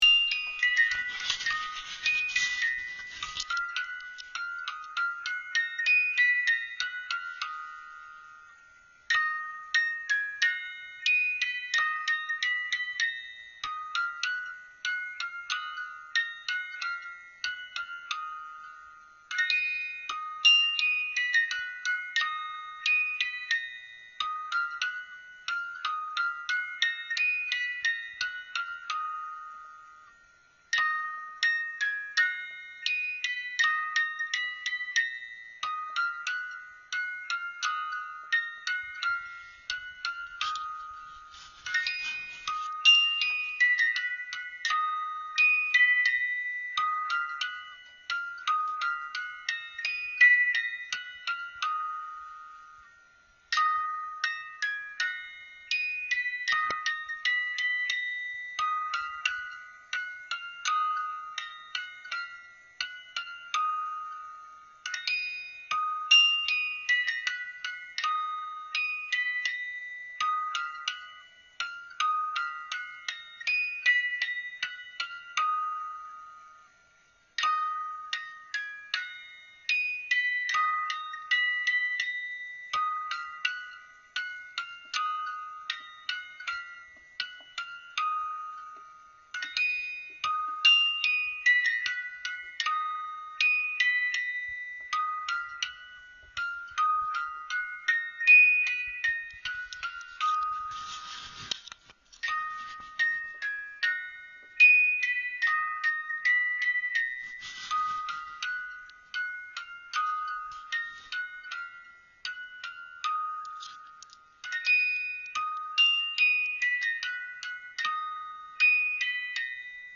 Grandchildren perform the screw winding of the music box of this doll in amusement, too.
雛祭オルゴール
雛祭オルゴール.mp3